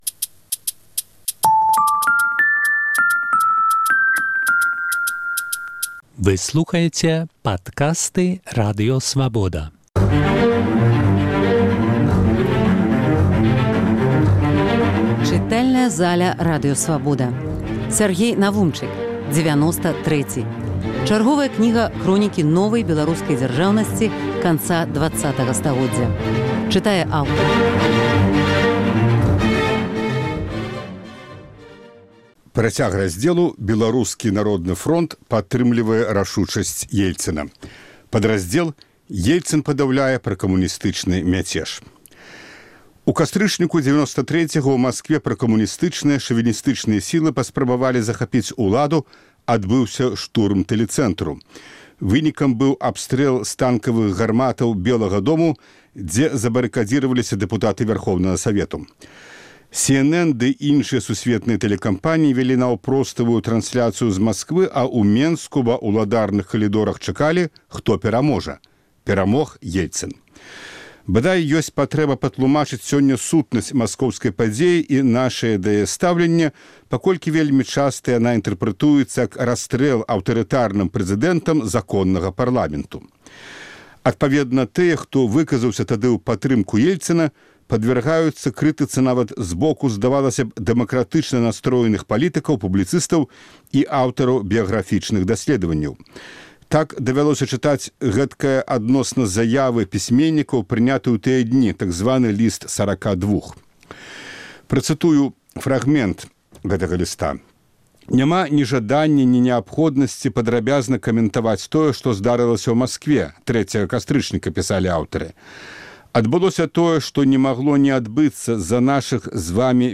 Фрагмэнты кнігі Сяргея Навумчыка "Дзевяноста трэці". Чытае аўтар.